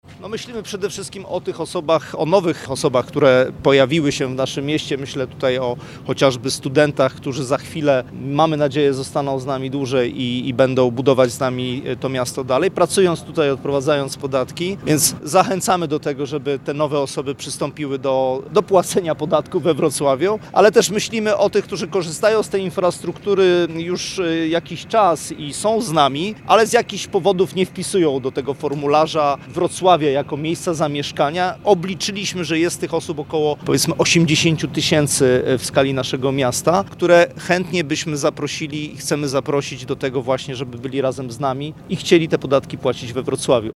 O tym do kogo jest skierowana kampania „Twój PIT zmienia Wrocław” mówił w rozmowie z Radiem LUZ Skarbnik Wrocławia, Marcin Urban.